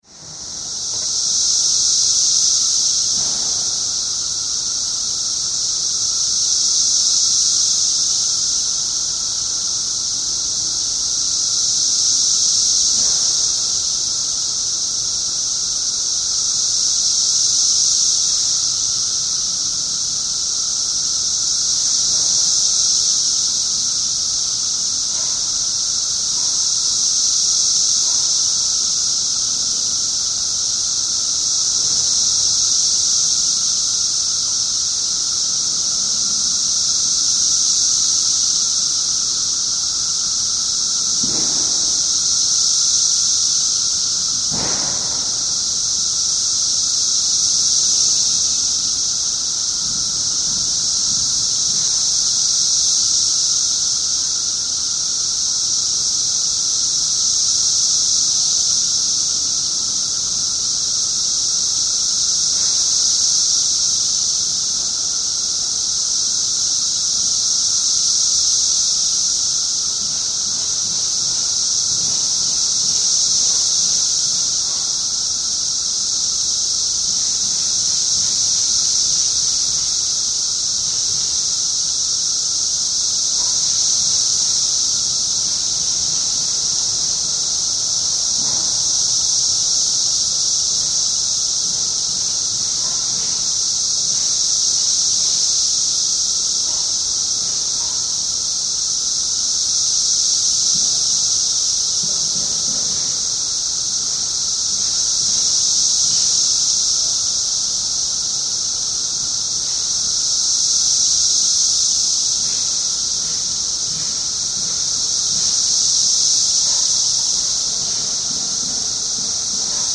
Excerpts of the 17-year Cicada Brood XIII from Glenview, IL (2007)
(N.B. Several factors influenced the intensity of male chirping. The total population of cicadas peaked in mid-June. Activity increased with higher daily temperatures and rising sun, while precipitation along with cooler air brought about a decrease or absence of chirping.)